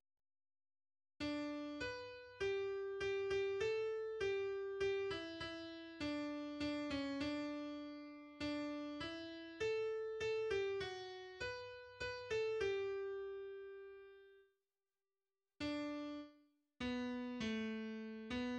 \version "2.12.3" \language "deutsch" \header { tagline = "" } \layout { indent = #0 } akkorde = \chordmode { \germanChords \set chordChanges = ##t } global = { \autoBeamOff \tempo 4 = 100 \time 3/4 \key g \major } melodie = \relative c' { \global r4 r d \repeat volta 2 { h'4 g( g8) g a4 g( g8) e e4 d( d8) cis d2 d4 e4 a( a8) g fis4 h( h8) a g2. r4 r d } \time 3/4 r4 h a4. h8 \bar ":|."